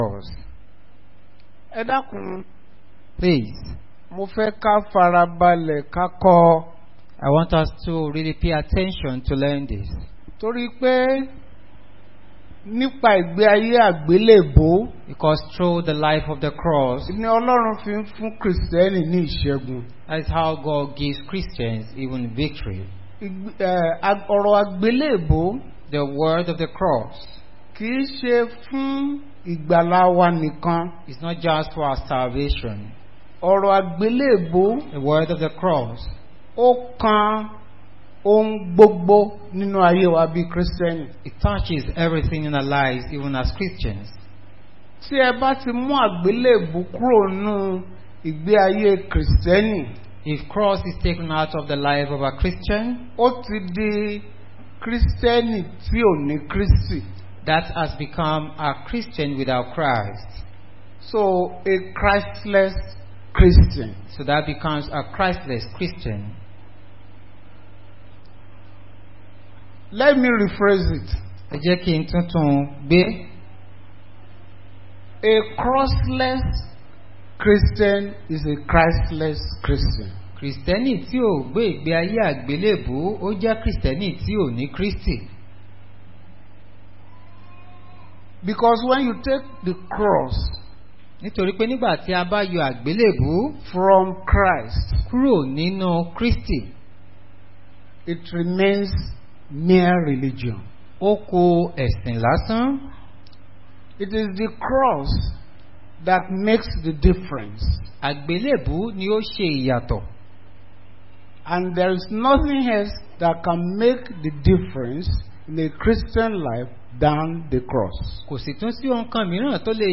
Bible Class